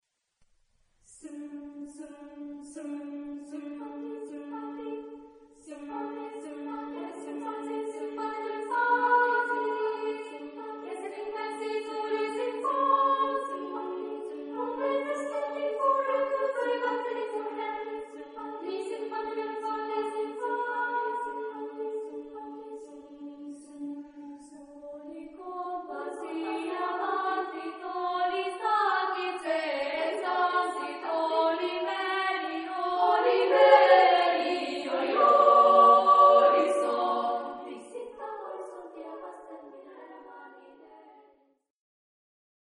Carácter de la pieza : vigoroso ; optimista ; feliz
Tipo de formación coral: SSA  (3 voces Coro infantil )
Tonalidad : re mayor